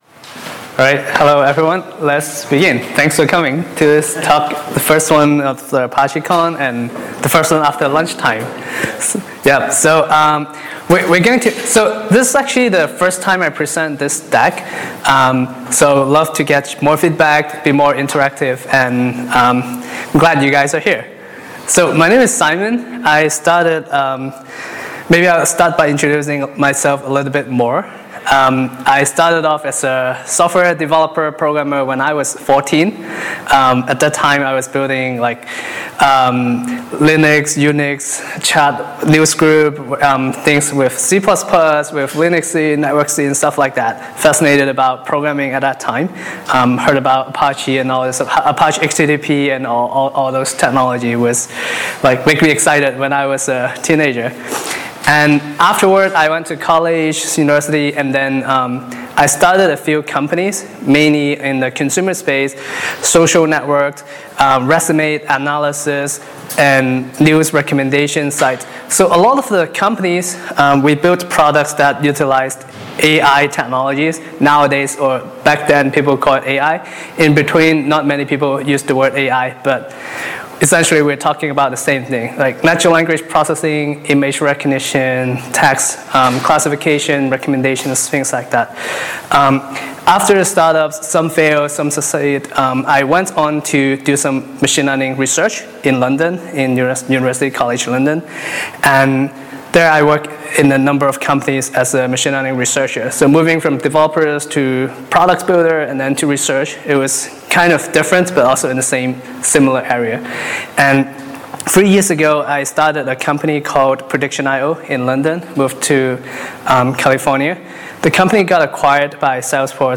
ApacheCon Seville 2016 – Crossing the AI Chasm